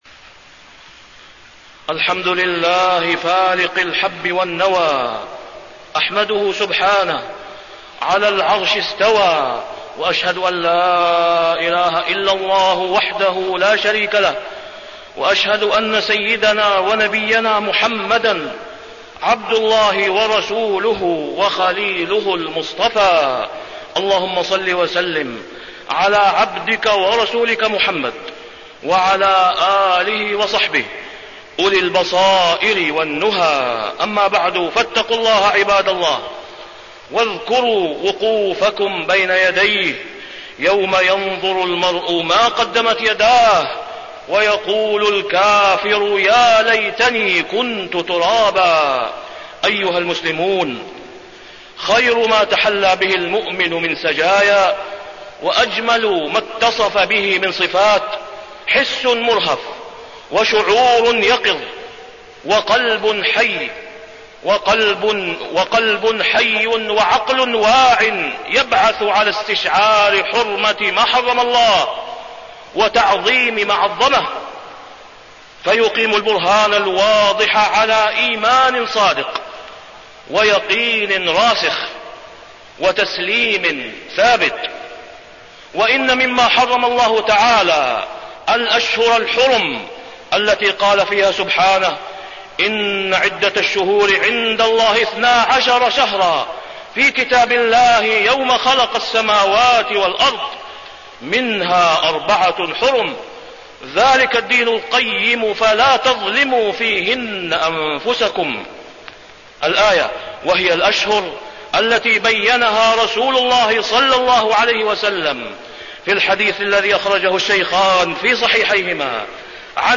تاريخ النشر ٣ رجب ١٤٢٧ هـ المكان: المسجد الحرام الشيخ: فضيلة الشيخ د. أسامة بن عبدالله خياط فضيلة الشيخ د. أسامة بن عبدالله خياط الأشهر الحرم